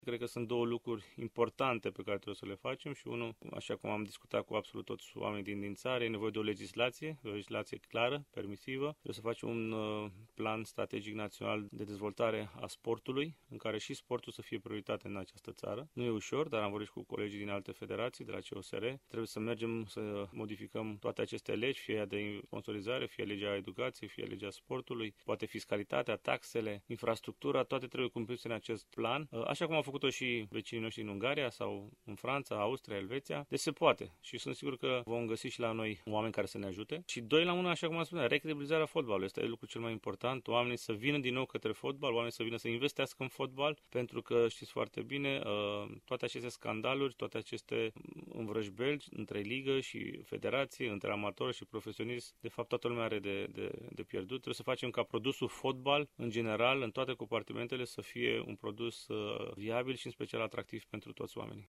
Aflat în plină campanie electorală pentru funcţia de preşedinte al Federaţiei Române de Fotbal, fostul mare fotbalist Ionuţ Lupescu (FOTO) a călcat şi pragul studioului nostru de radio, acordând două interviuri, unul în direct, în cadrul emisiunii „Bună Dimineaţa, Transilvania!”, celălalt înregistrat, pentru emisiunea sportivă „Weekend împreună, Repriza de Seară”, difuzată sâmbăta şi duminica, între orele 18:00- 20:00.